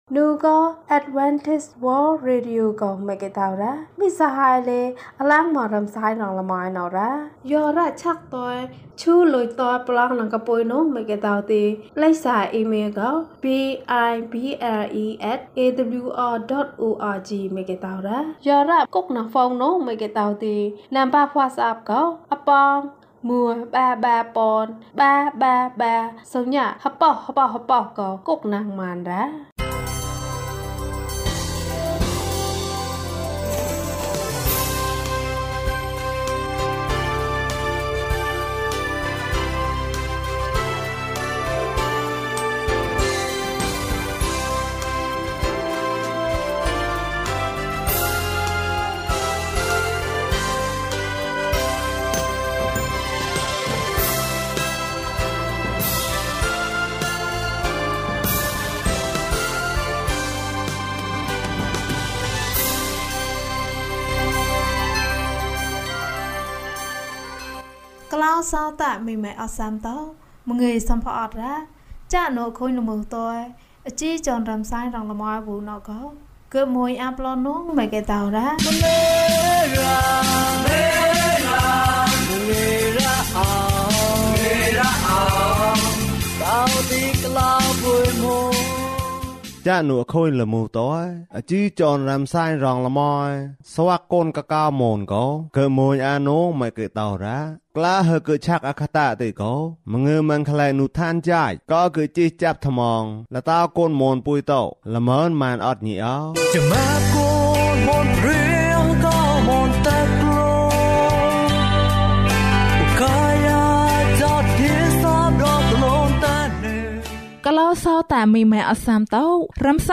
ခရစ်တော်ထံသို့ ခြေလှမ်း ၅၀ ကျန်းမာခြင်းအကြောင်းအရာ။ ဓမ္မသီချင်း။ တရားဒေသနာ။